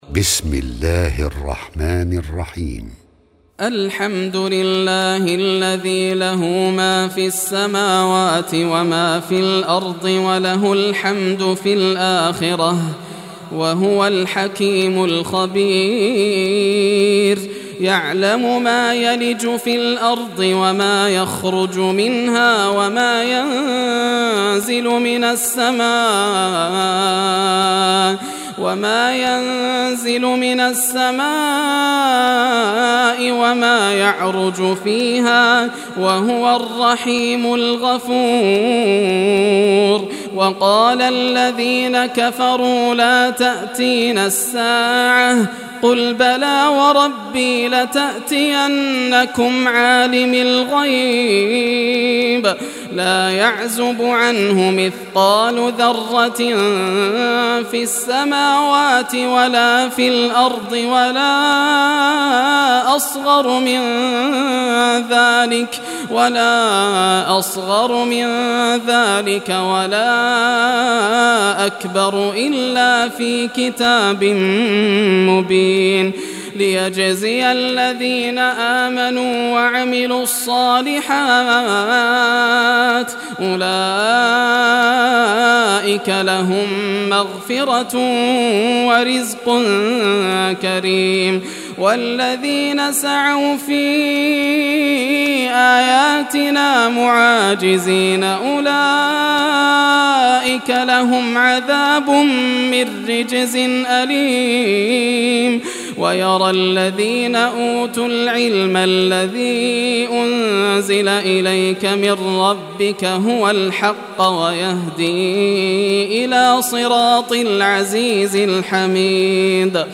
Surah Saba Recitation by Yasser al Dosari
Surah Saba, listen or play online mp3 tilawat / recitation in Arabic in the beautiful voice of Sheikh Yasser al Dosari.